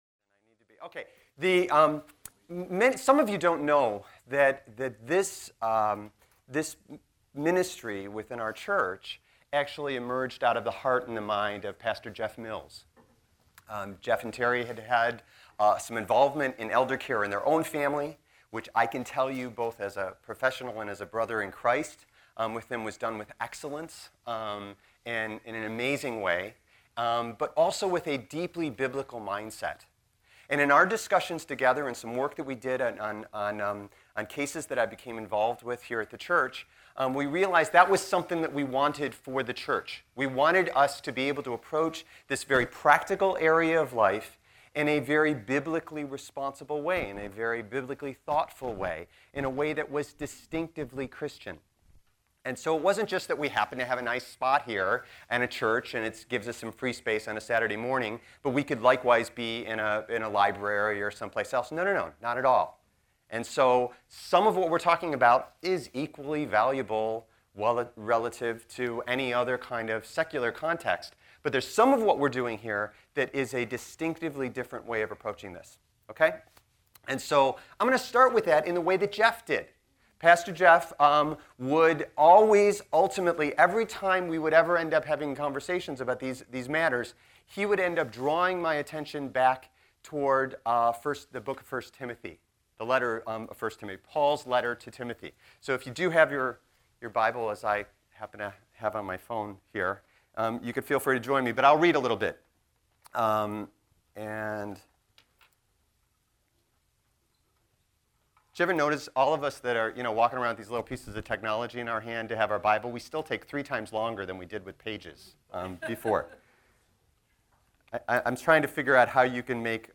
Here, you can find audio from past workshops.